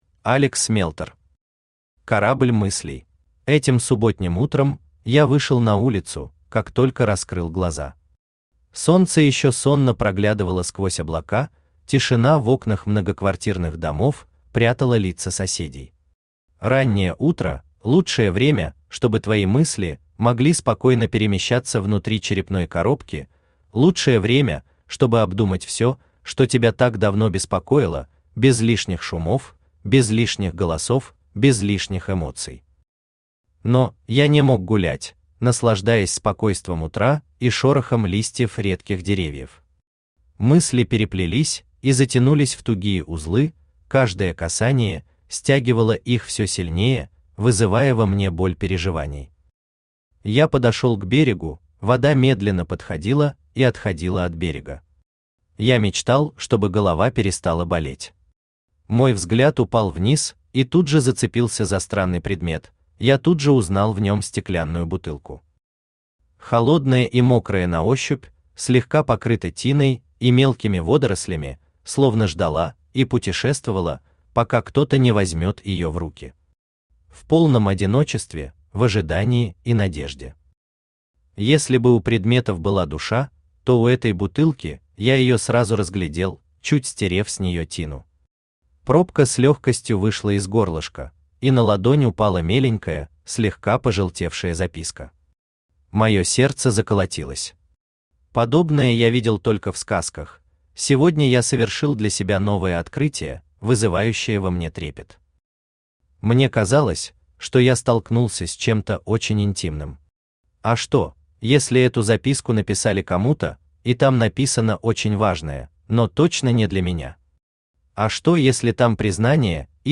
Аудиокнига Корабль мыслей | Библиотека аудиокниг
Aудиокнига Корабль мыслей Автор Алекс Мелтор Читает аудиокнигу Авточтец ЛитРес.